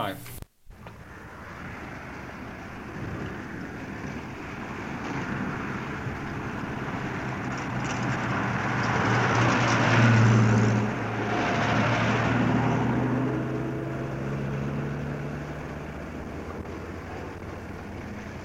复古卡车 " G1405卡车背景
描述：卡车在路上行驶。快速进出。适合背景。 这些是20世纪30年代和20世纪30年代原始硝酸盐光学好莱坞声音效果的高质量副本。 40年代，在20世纪70年代早期转移到全轨磁带。我已将它们数字化以便保存，但它们尚未恢复并且有一些噪音。
Tag: 卡车 交通运输 光学 经典